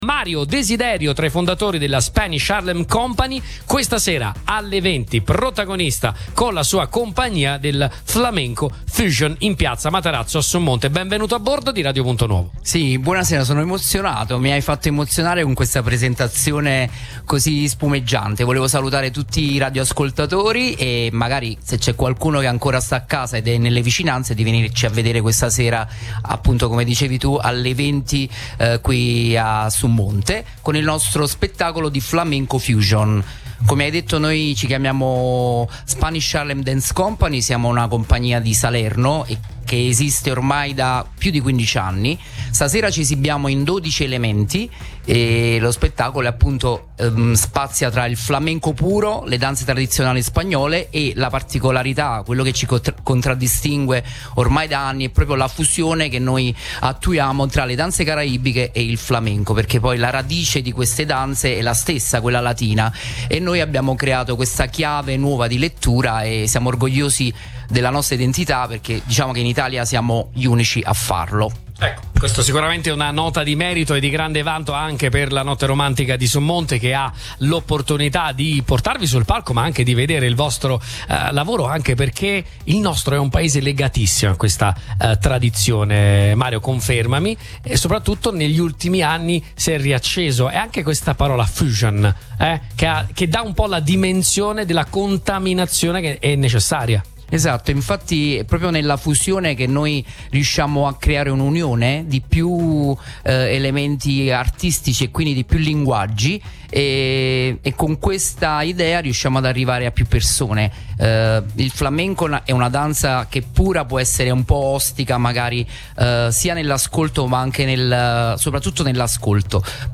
Sabato 21 giugno, in occasione del solstizio d’estate, il borgo di Summonte, tra i più belli d’Italia, ha ospitato una nuova edizione della Notte Romantica, evento patrocinato dal Comune di Summonte, in collaborazione con Generali Avellino Italia e Radio Punto Nuovo.
Ai nostri microfoni anche il consigliere comunale con delega agli eventi, Angelo Maccario, che ci ha raccontato l’entusiasmo del debutto: